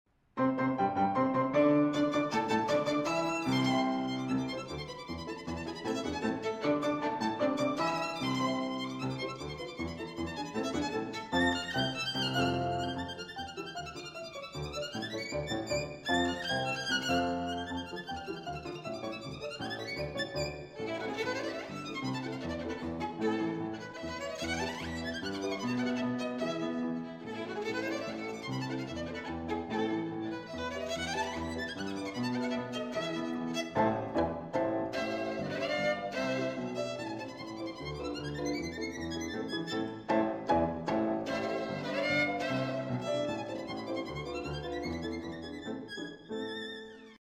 These samples were all recorded using Borman Instruments.
Violin
Live performance on her Borman of Sarasate - Zigeunerweisen for the Canadian Broadcasting Company.